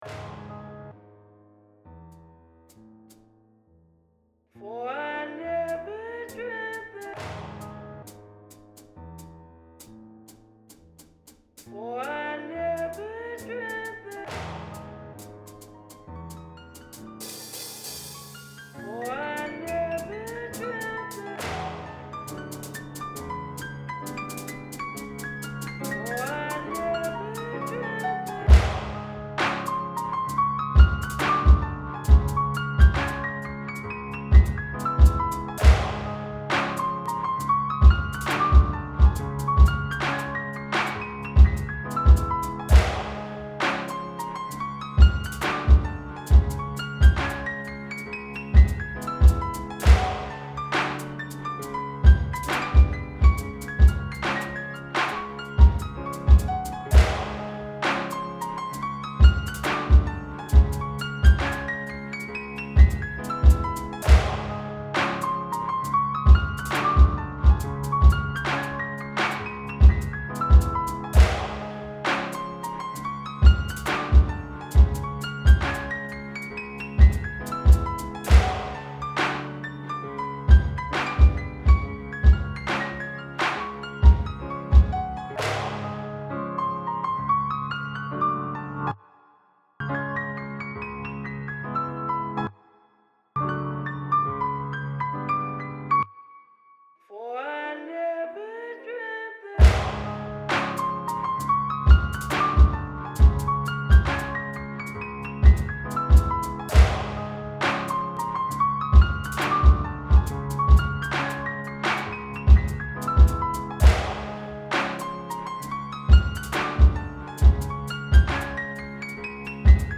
That sample is sumptuous.
Then those drums kick in and boosh, we are off!